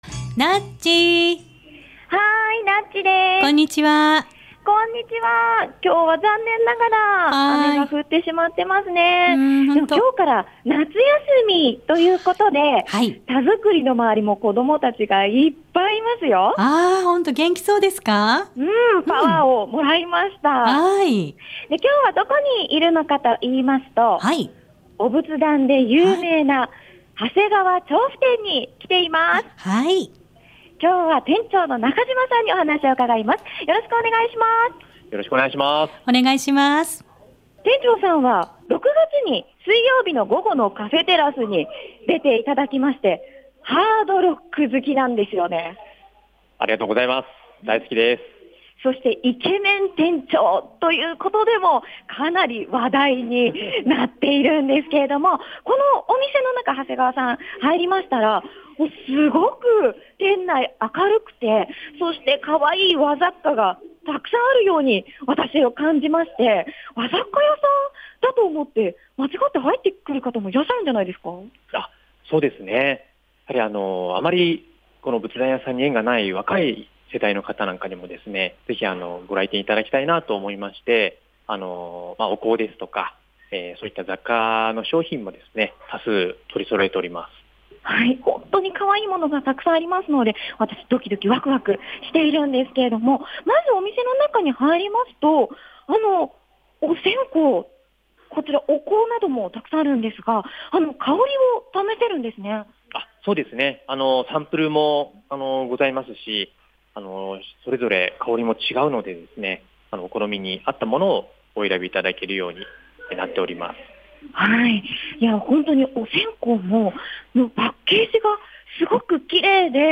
午後のカフェテラス 街角レポート
レポートの中で音色を聴かせて頂きました♪ 全て音が違います！！ 綺麗で心にスーッと入ってくる音色を是非聴いてみて下さいね♪ お仏壇！